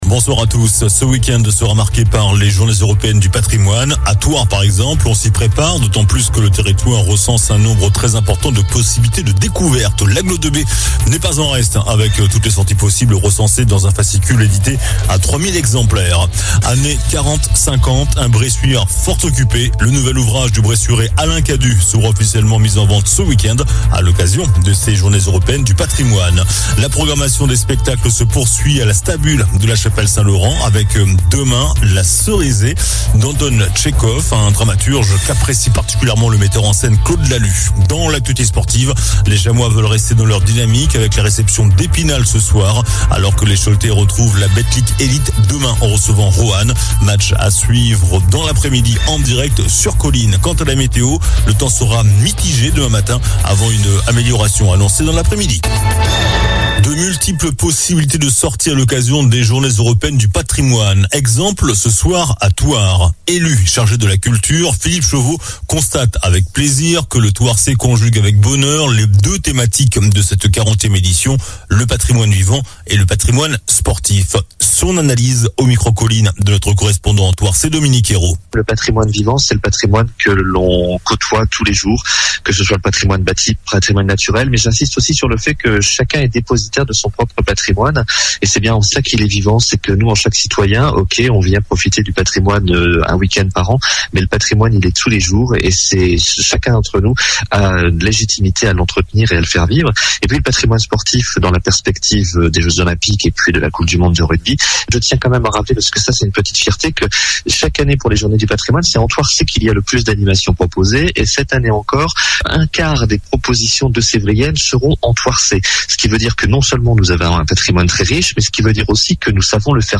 JOURNAL DU VENDREDI 15 SEPTEMBRE ( SOIR )